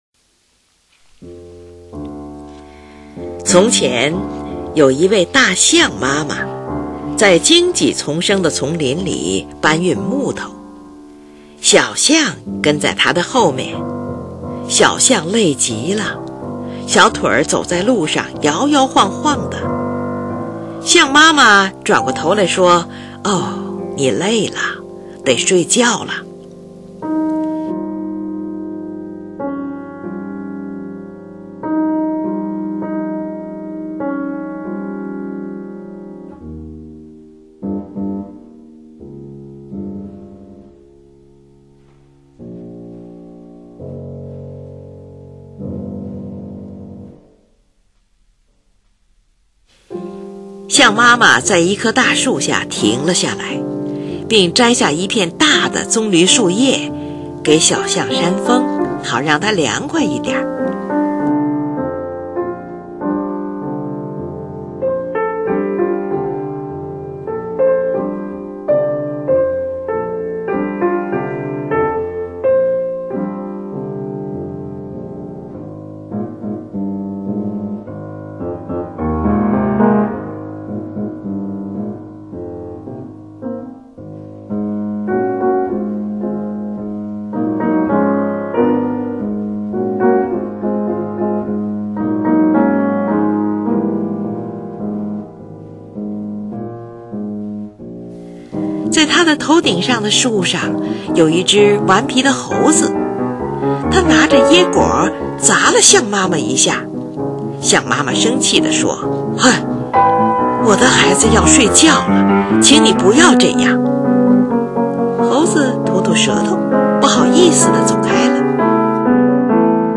乐曲又一个简单的引子，单调的声响象在描述小象摇摇摆摆的走路声。
第一与第三部分，是节奏较缓慢的。
第一段的伴奏总是保持同样的和弦与节奏，酝酿出不急不缓的韵律；
第二段速度较快，音乐一下子活泼开朗了起来，较多半音地进行；
第三段中的乐句十分明亮而柔和。